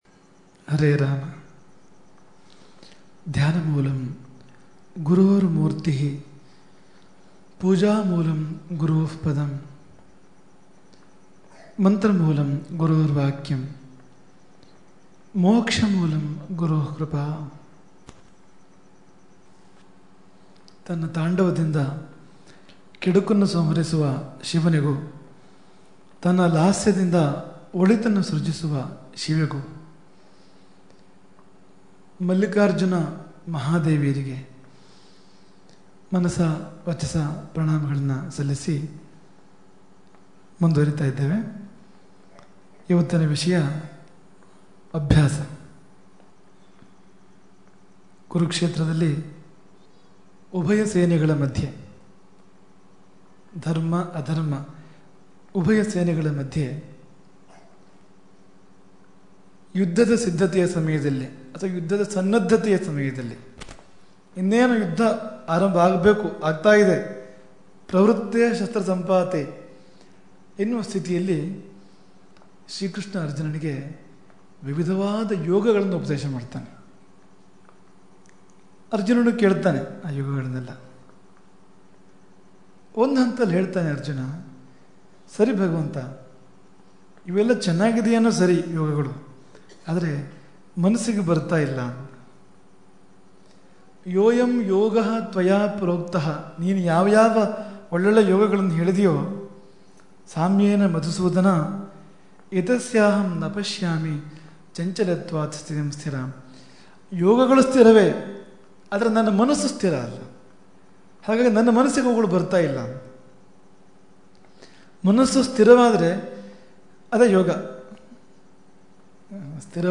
11-ಅಗೋಸ್ತು-2010 ಅಶೋಕೆಯಲ್ಲಿ ನಡೆಯುತ್ತಿರುವ ಹದಿನೇಳನೇ ಚಾತುರ್ಮಾಸ್ಯದ ಶುಭಾವಸರದಲ್ಲಿ, ಶ್ರೀ ಶ್ರೀ ರಾಘವೇಶ್ವರ ಭಾರತೀ ಮಹಾಸ್ವಾಮಿಗಳವರ ದಿವ್ಯ ವಚನಾಮೃತಧಾರೆಯಲ್ಲಿ ಹರಿದುಬರುತ್ತಿರುವ ಪ್ರವಚನ ಮಾಲಿಕೆ.